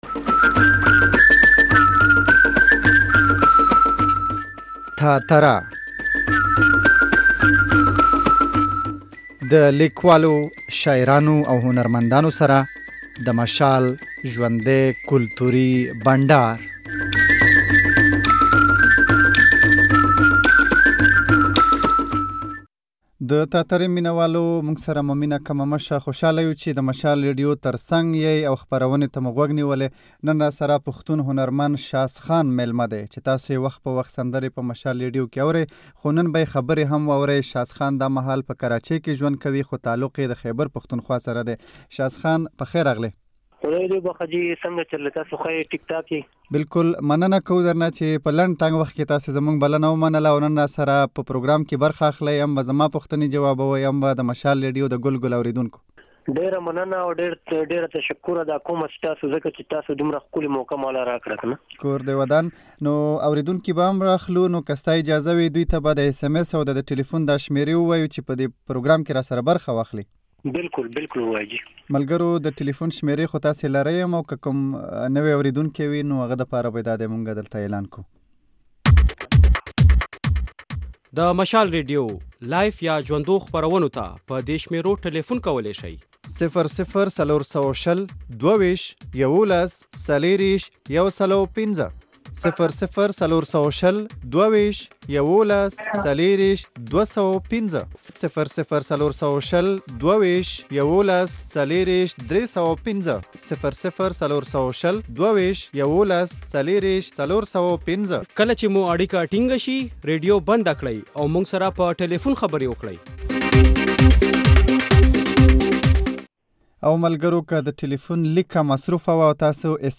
بنډار